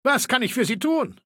Datei:Maleold01 ms06 greeting 00027f97.ogg